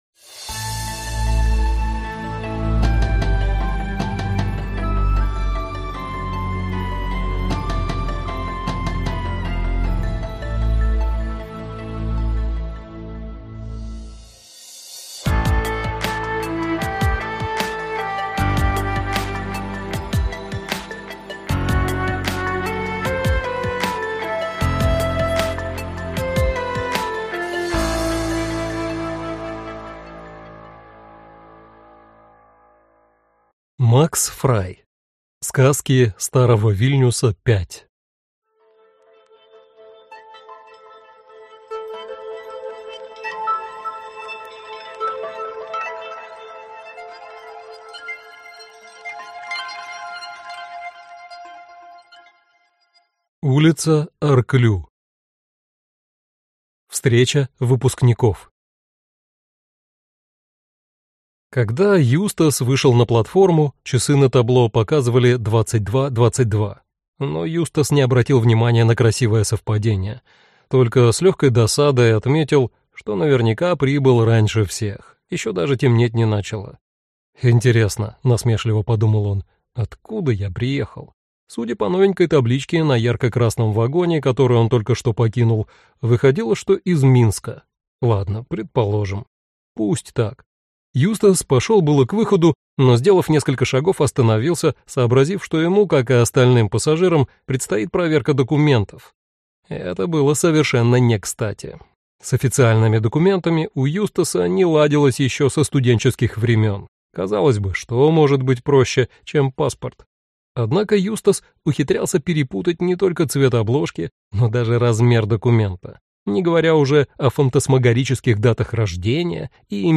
Аудиокнига Сказки старого Вильнюса V - купить, скачать и слушать онлайн | КнигоПоиск